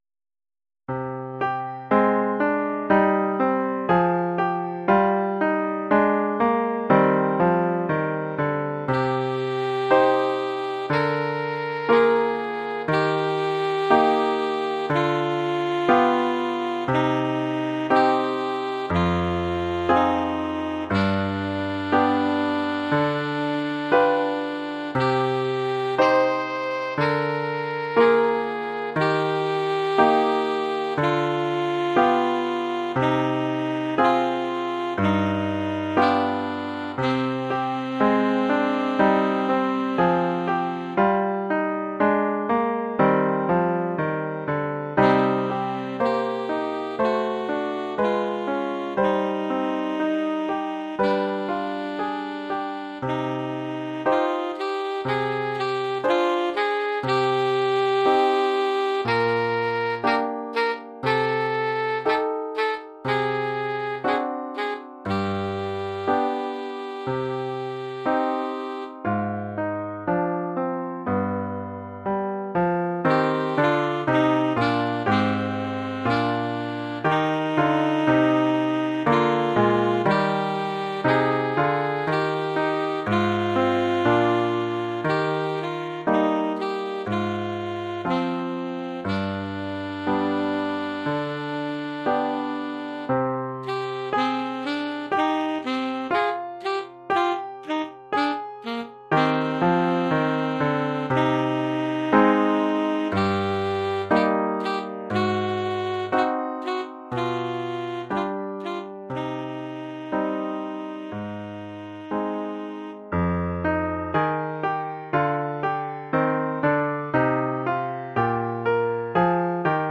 Oeuvre pour saxophone sib et piano.